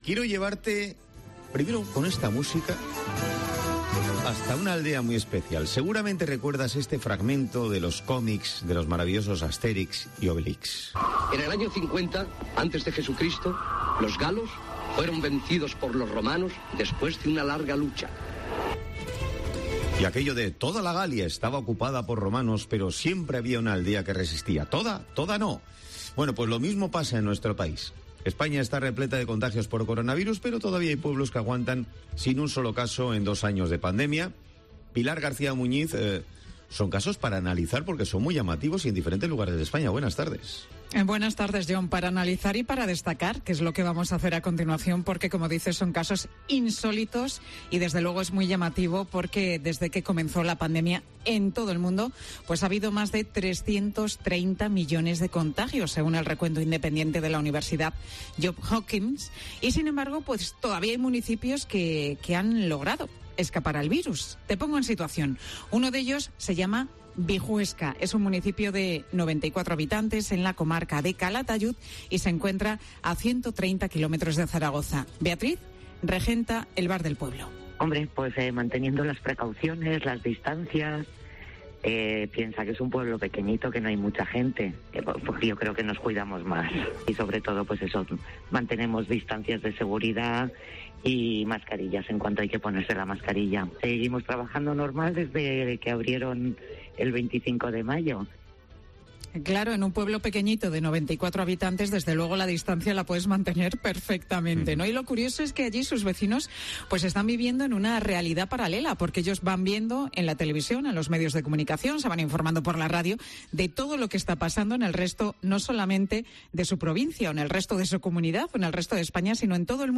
Hablamos con Ángel Martín, alcalde de Bijuesca